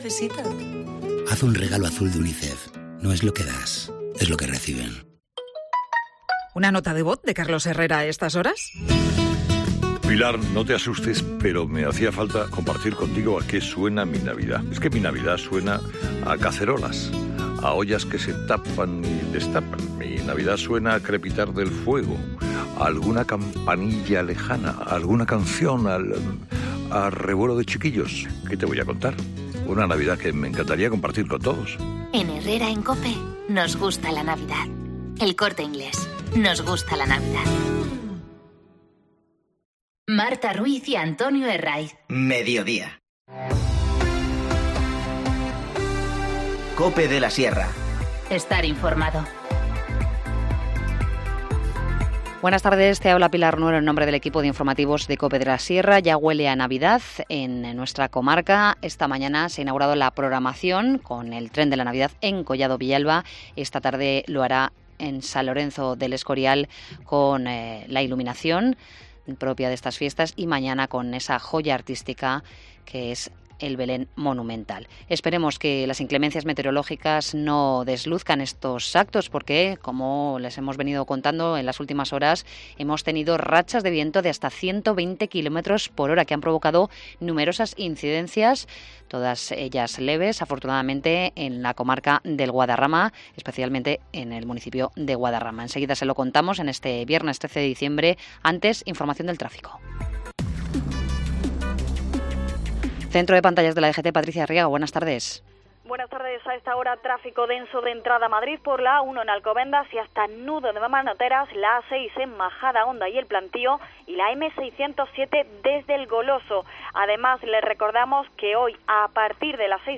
Informativo Mediodía 13 diciembre 14:20h